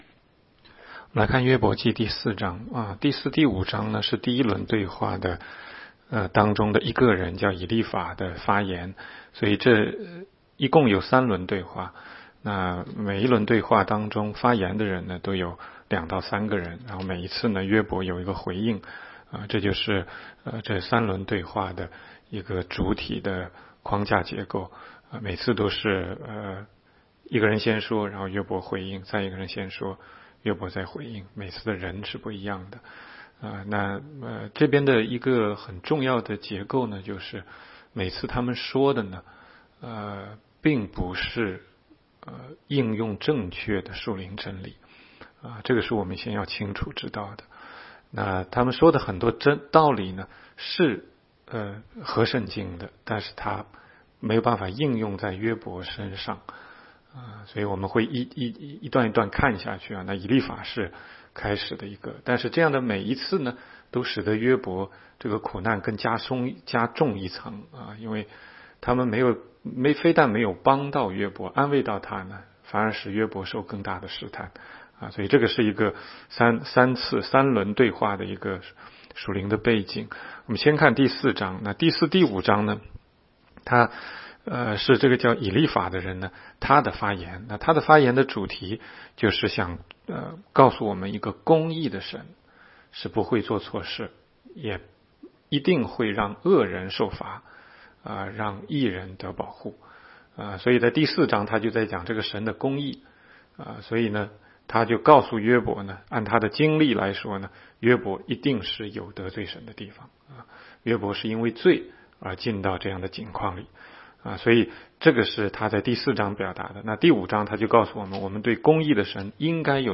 16街讲道录音 - 每日读经-《约伯记》4章